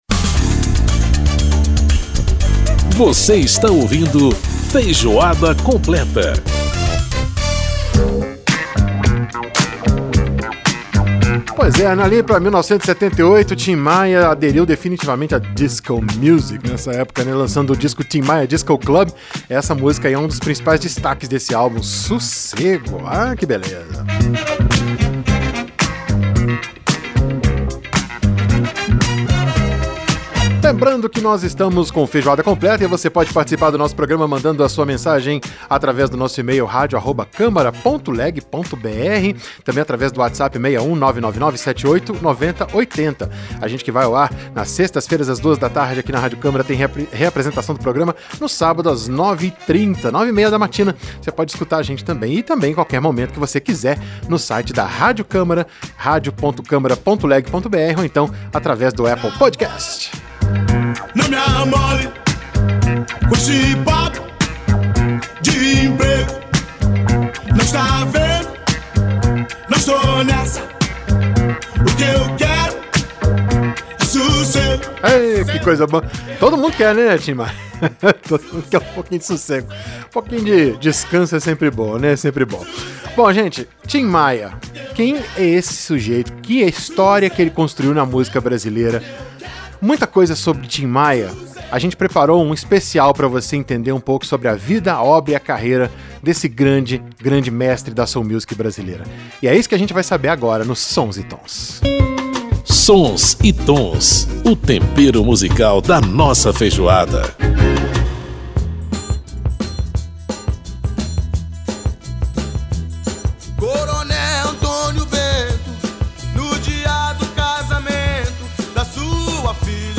Para saber mais sobre o assunto, conversamos com a deputada Soraya Santos (PL-RJ), autora do pedido para a criação e instalação da Comissão Especial.